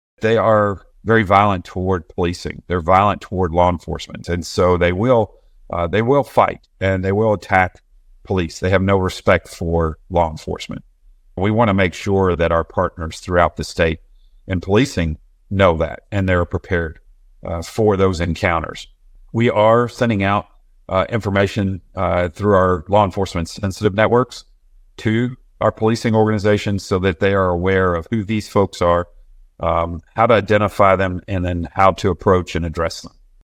The TBI Director also said the gang has no respect for law enforcement.(AUDIO)